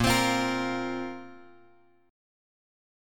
A#sus2 chord {6 x x 5 6 6} chord
Asharp-Suspended 2nd-Asharp-6,x,x,5,6,6.m4a